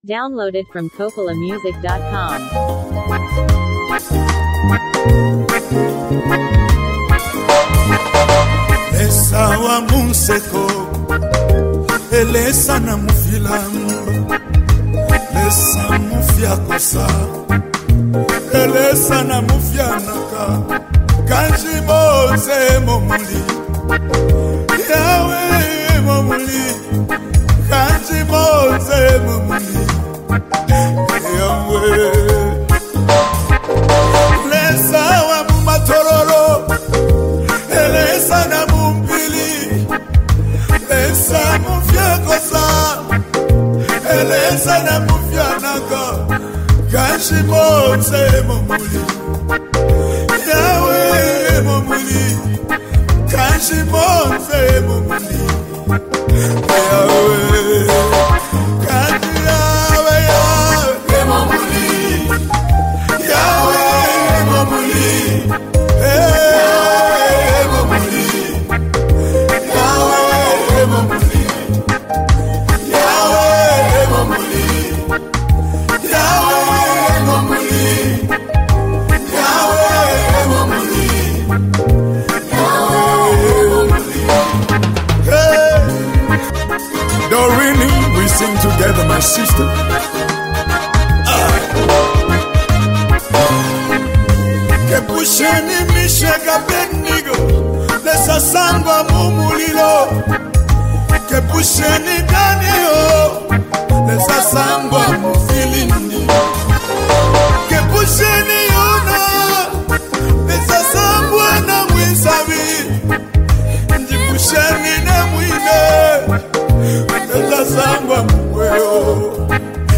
a powerful and worship-filled song